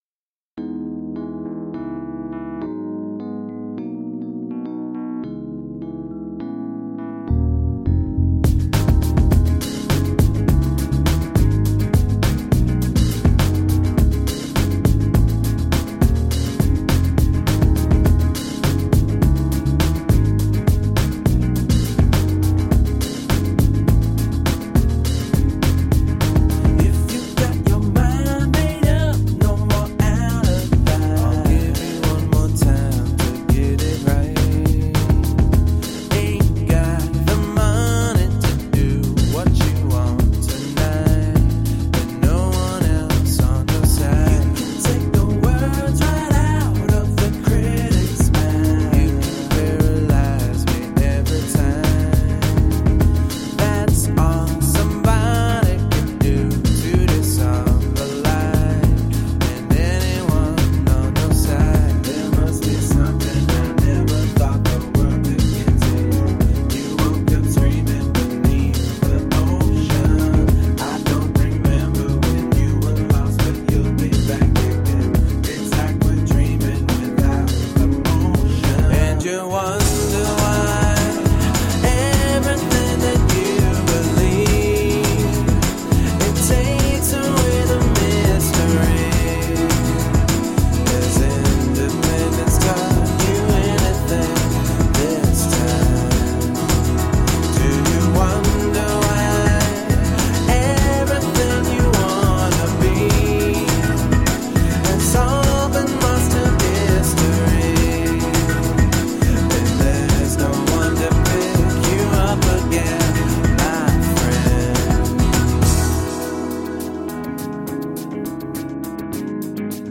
Soulful electro pop.
Tagged as: Electro Rock, Rock, Electro Pop